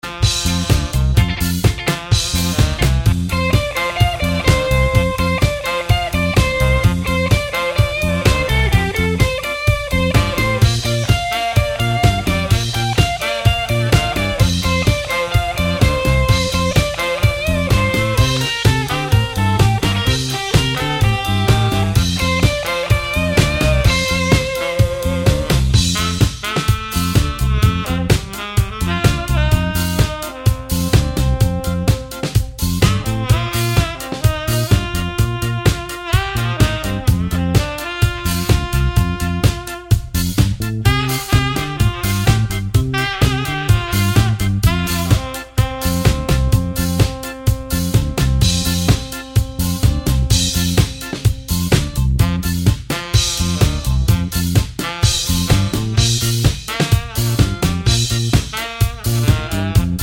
no Backing Vocals Ska 2:57 Buy £1.50